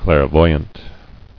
[clair·voy·ant]